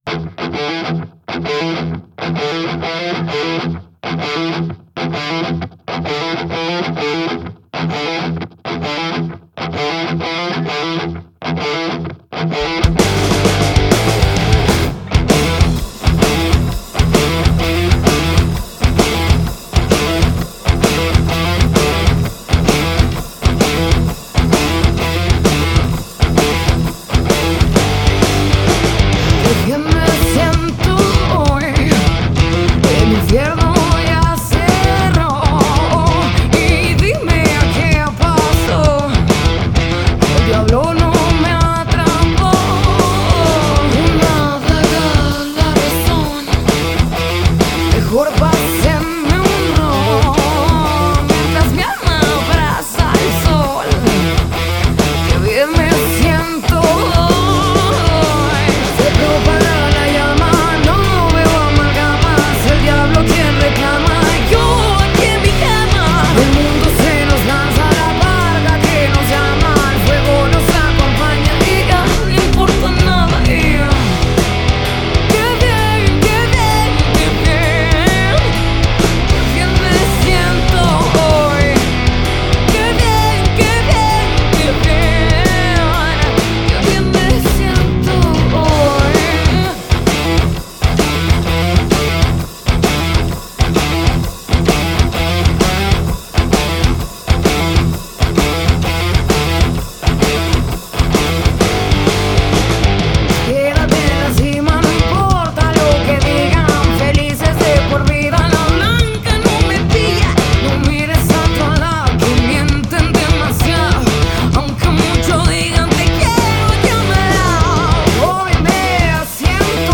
con una voz que atrape por su destreza y calidez.